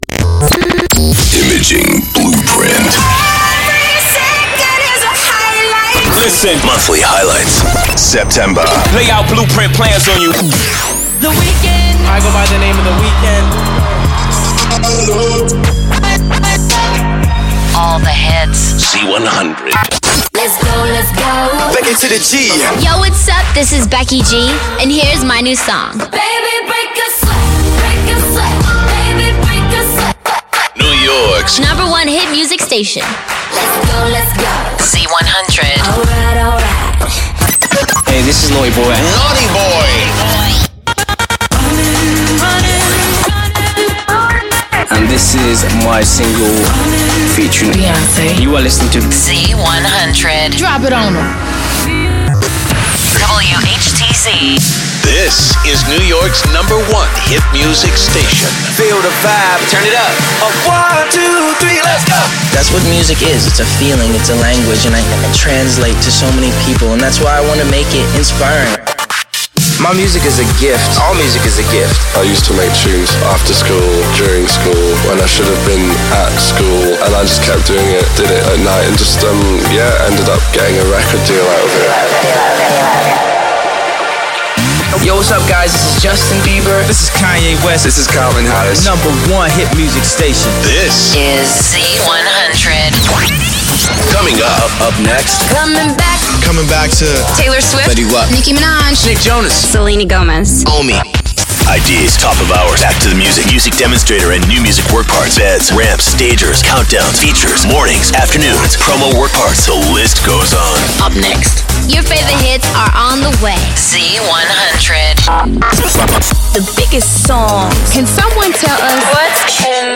Our client KIIS is used to demonstrate 'IB' production alongside the world famous Z100.